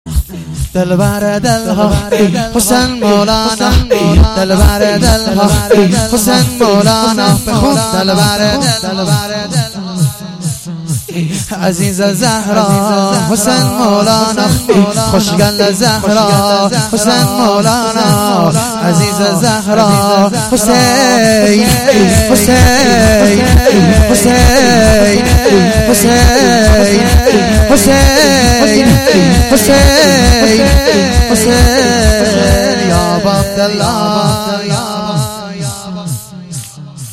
نوا و ذکر | دلبر دلها حسین مولانا
مناجات با امام حسین(علیه السلام)